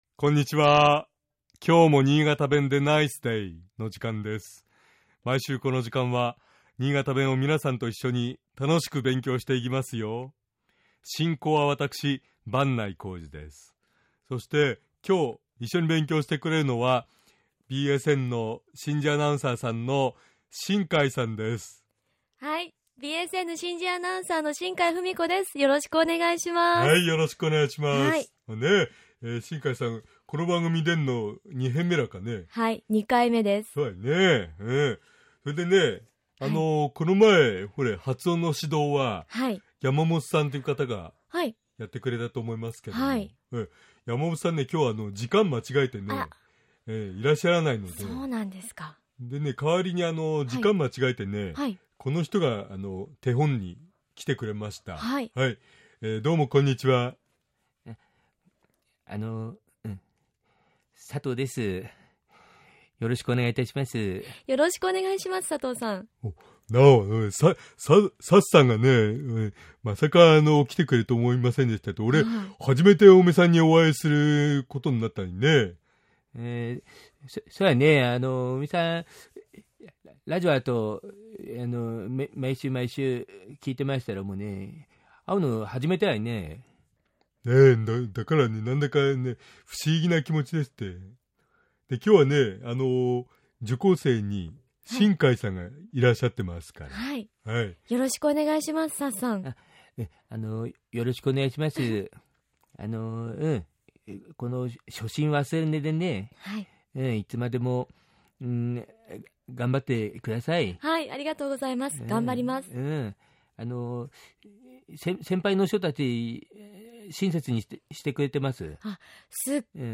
尚、このコーナーで紹介している言葉は、 主に新潟市とその周辺で使われている方言ですが、 それでも、世代や地域によって、 使い方、解釈、発音、アクセントなどに 微妙な違いがある事を御了承下さい。